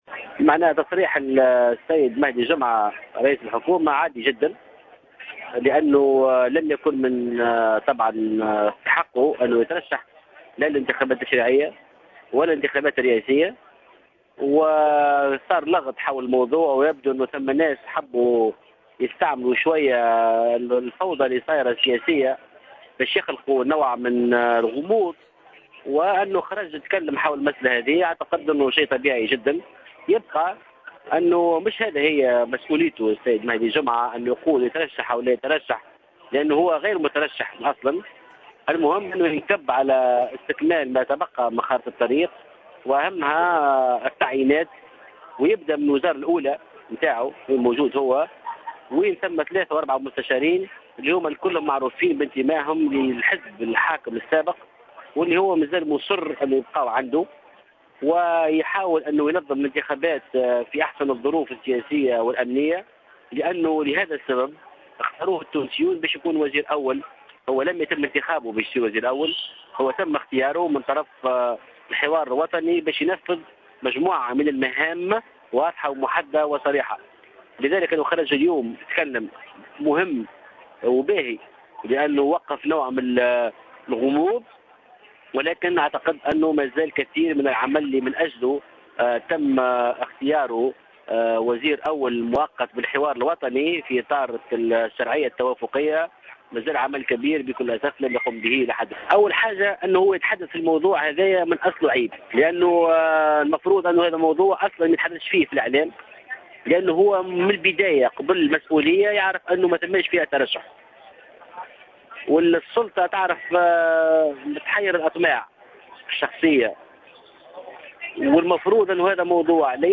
أكد القيادي بحركة نداء تونس محسن مرزوق في تصريح لجوهرة "اف ام" أن تصريح مهدي جمعة يعد أمرا طبيعيا جدا لأنه ليس من حقه الترشح للإنتخابات التشريعية والرئاسية وأن كان يعلم منذ البداية ان منصبه لايخول له الترشح للإنتخابات قائلا إن تونس لاحاجة لها بأبطال مزيفين وأبطال صدفة على حد قوله.